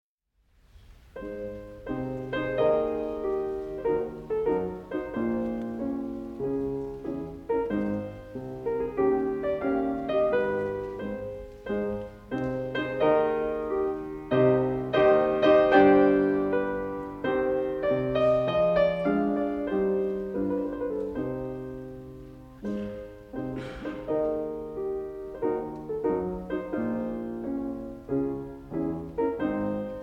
LIVE RECORDING OF A CONCERT IN THE SALA VERDI, CONSERVATORIO